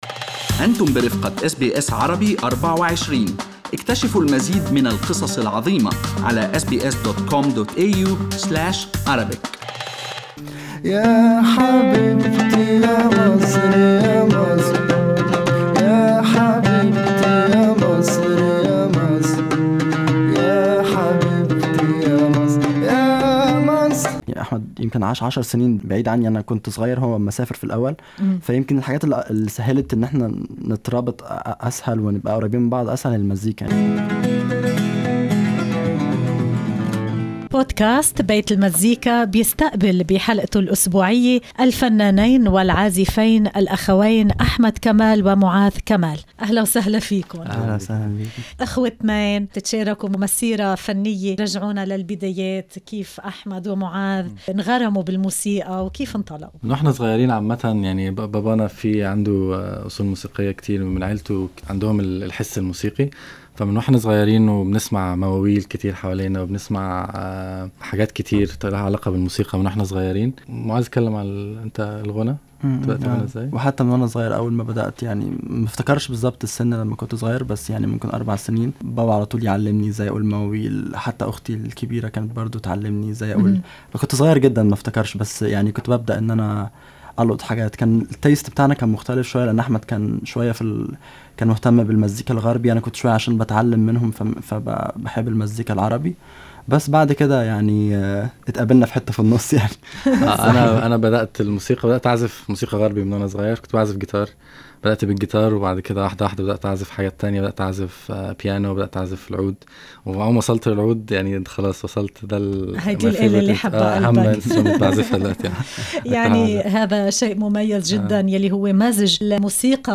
singers from Egypt
العود الشرقي بالغيتار الغربي
بصوته المصري المتألق